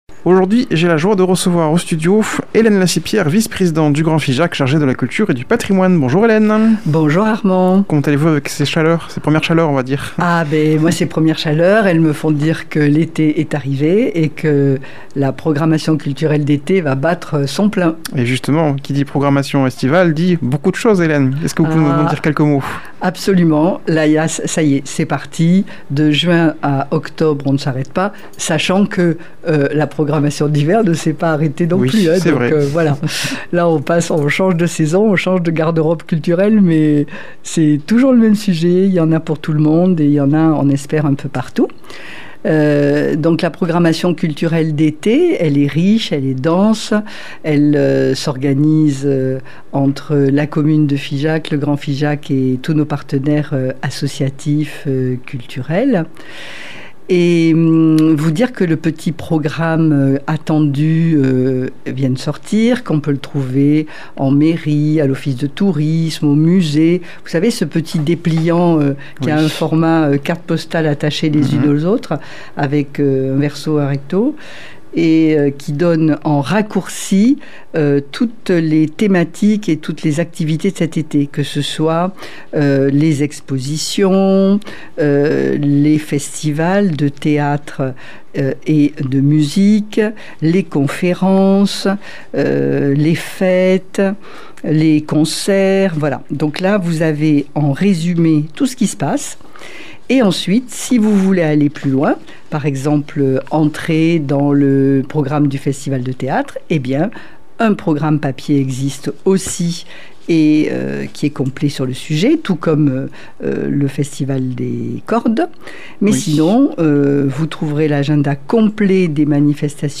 a comme invitée au studio Hélène Lacipière, Vice-Présidente du Grand-Figeac chargée de la culture et du patrimoine. Elle vient évoquer l’édition 2025 du Festival de Théâtre de Figeac et la programmation estivale